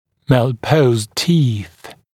[mæl’pəuzd tiːθ][мэл’поузд ти:с]неправильно расположенные зубы